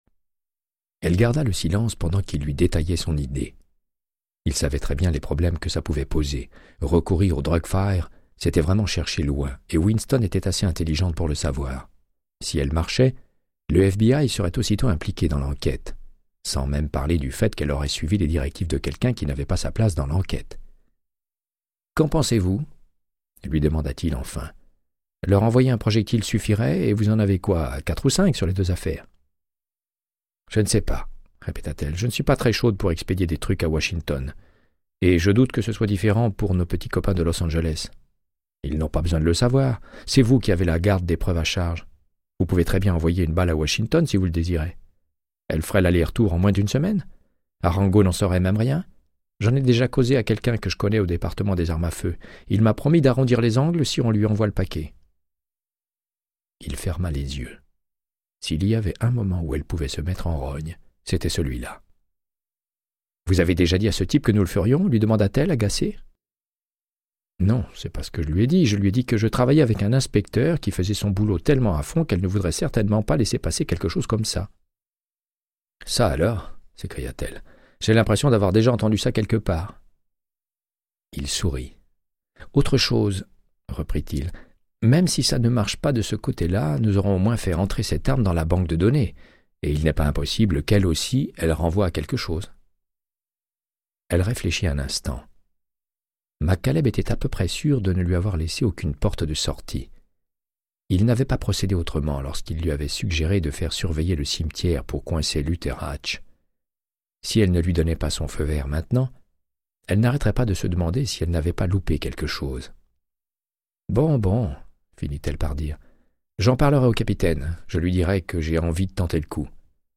Audiobook = Créance de sang, de Michael Connellly - 55